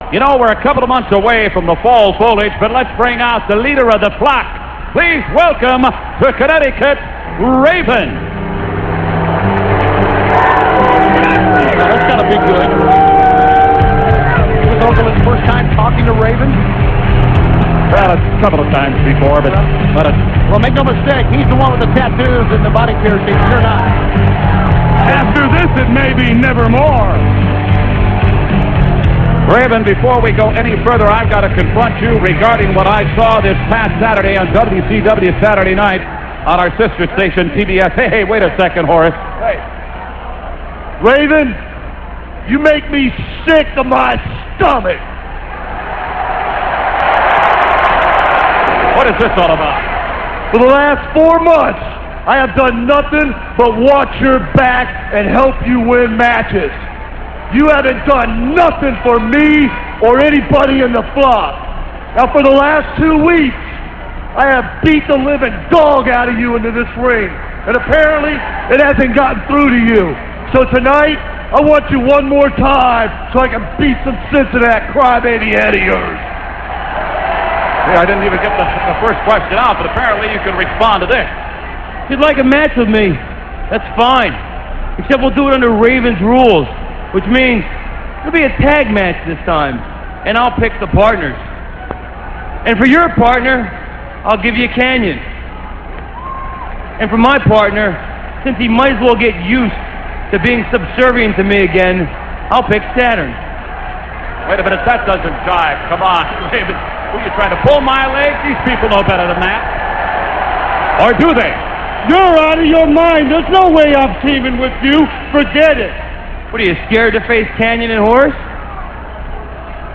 - This interview comes from Nitro - [8.17.98]. J.J. Dillon sets up a tag match between Horace and Saturn against Raven and Kanyon and if anybody turns against their partner they'll be suspended for 90 days from WCW.